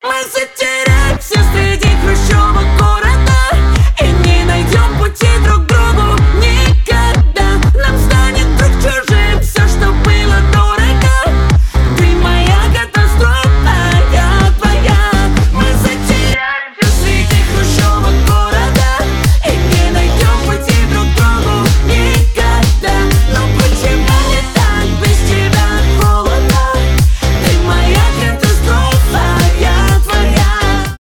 громкие
танцевальные , зажигательные